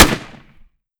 7Mag Bolt Action Rifle - Gunshot A 005.wav